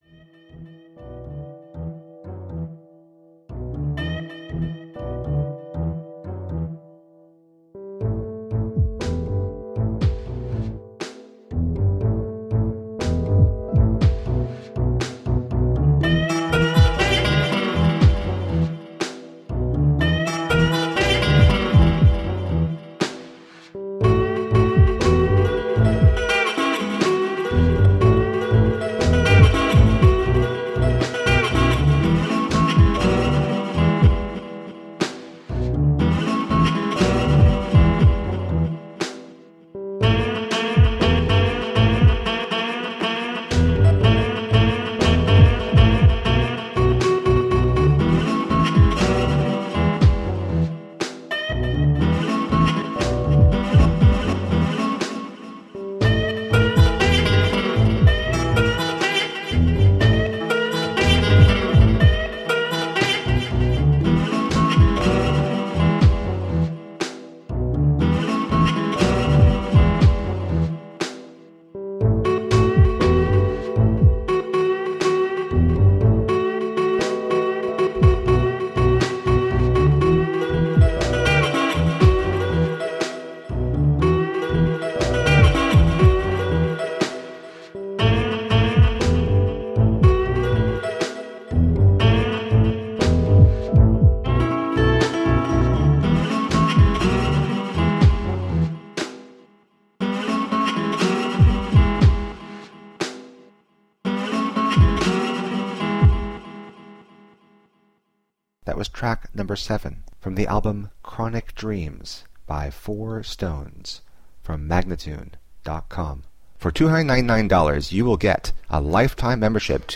Groove soaked ambient chill.
Alt Rock, Rock, Happy Hour, Remix